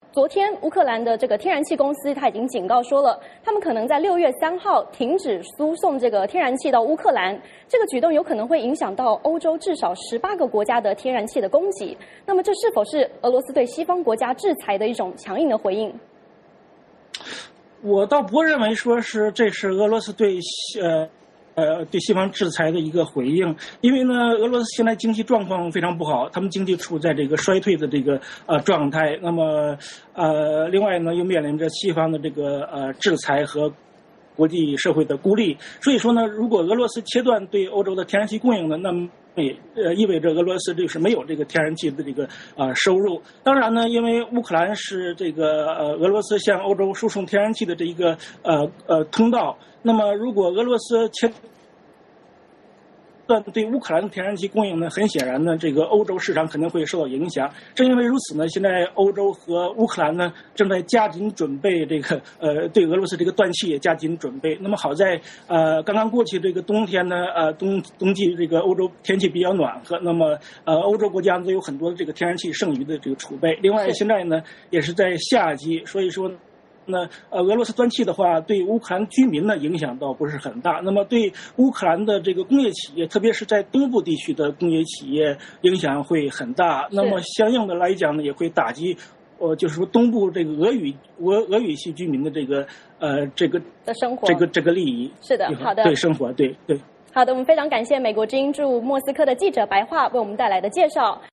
VOA连线：俄警告将停止输送天然气 双方关系愈演愈僵？